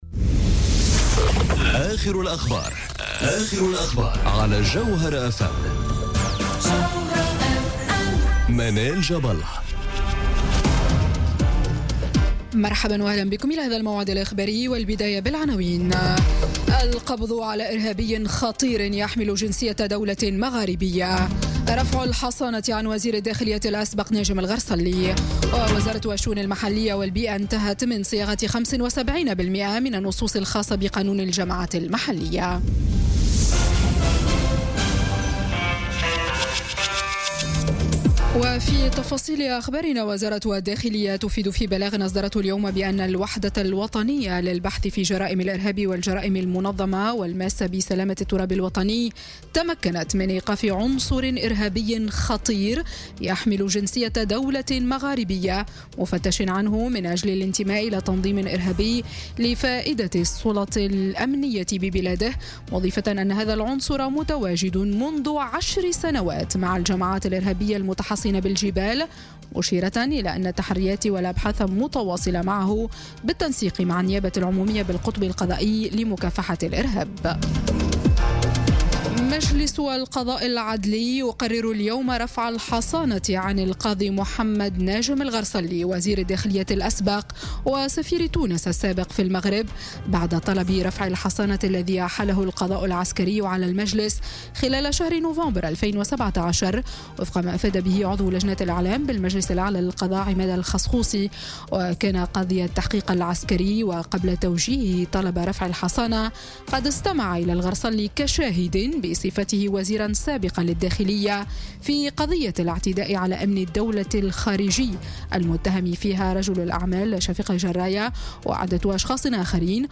نشرة أخبار السابعة مساءً ليوم الثلاثاء 2 جانفي 2018